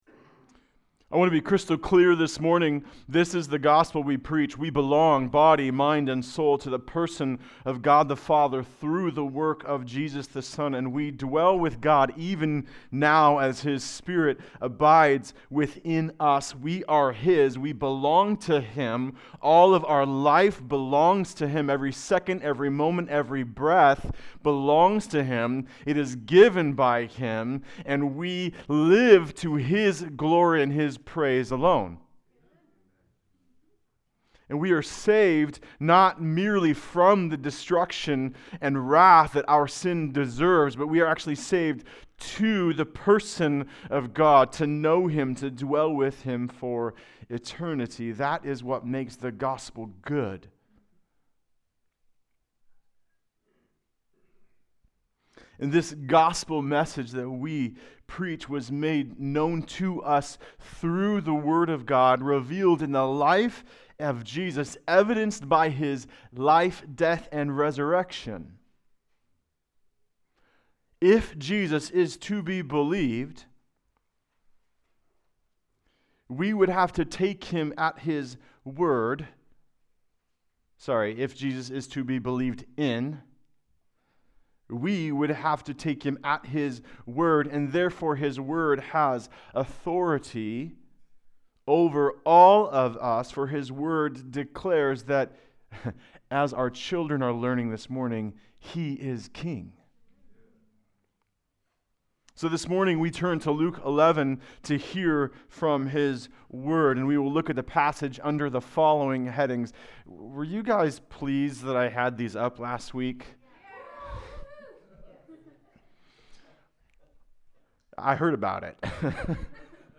Passage: Luke 11: 14-28 Service Type: Sunday Service